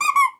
squeaky_rubber_toy_cartoon_01.wav